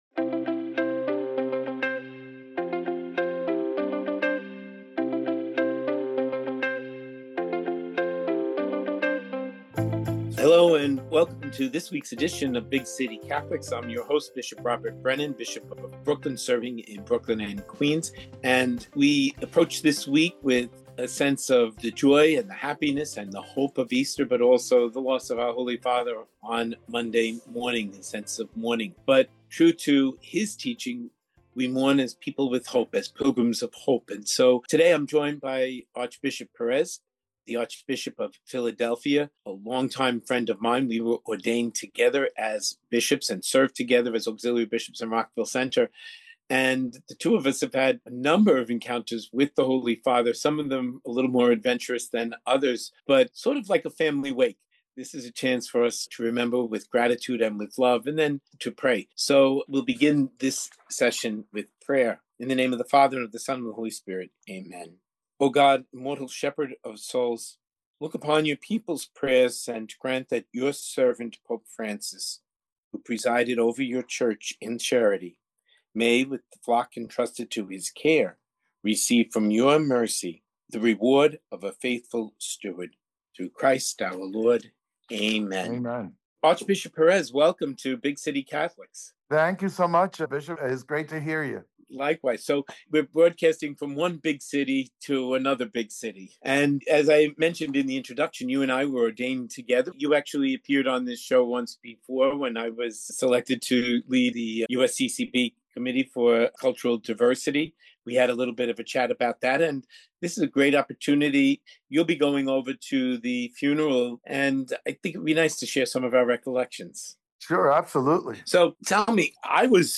In this episode of Big City Catholics, Bishop Robert J. Brennan is joined by his long-time friend Archbishop Nelson Pérez of Philadelphia.